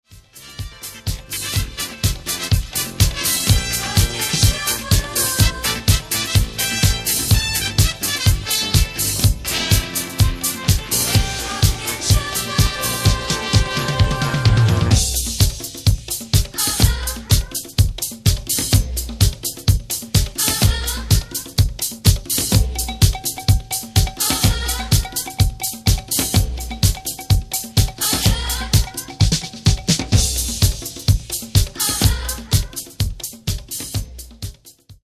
Genre:   Latin Disco